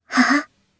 Kara-Vox_Happy2_jp.wav